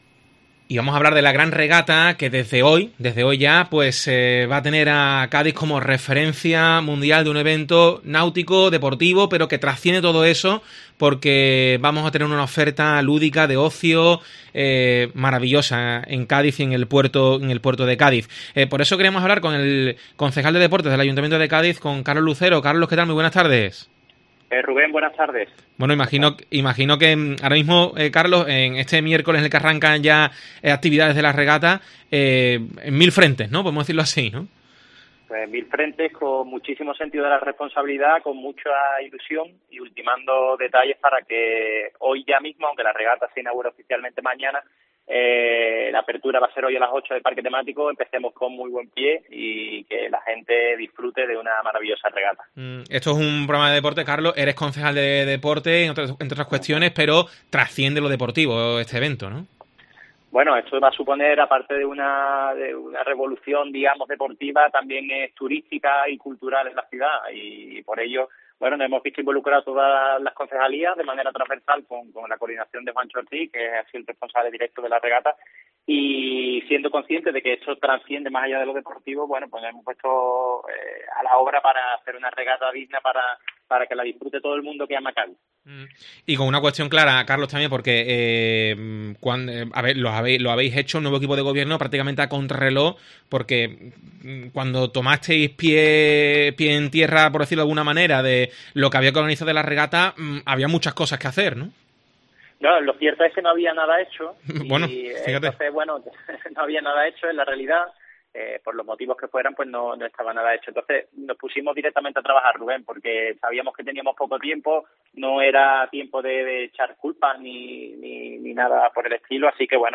Carlos Lucero, concejal de Deportes, habla sobre la Gran Regata en COPE